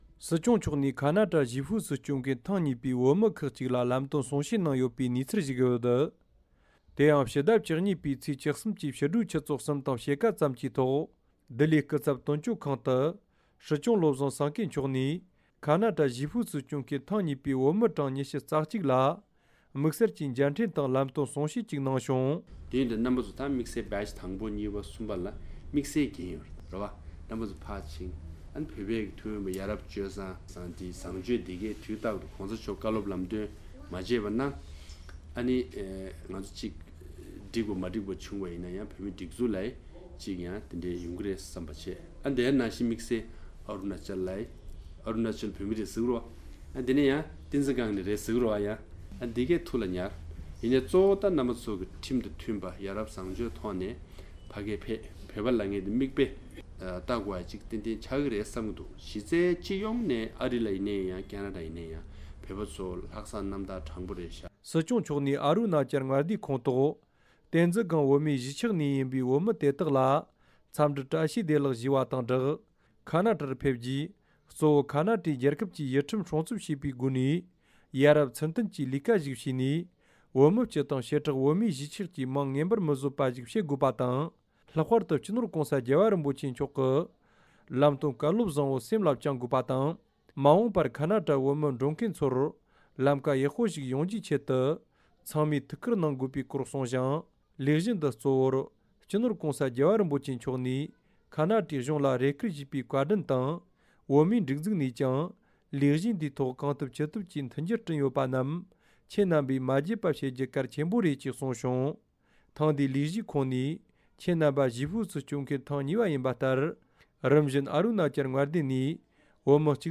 སྲིད་སྐྱོང་མཆོག་གིས་ཨ་རུ་ན་ཅལ་ནས་ཁེ་ན་ཌར་གཞིས་སྤོས་གནང་མཁན་ཐེངས་གཉིས་པའི་བོད་མི་༢༡ལ་ལམ་སྟོན་གསུང་བཤད་གནང་ཡོད་པ།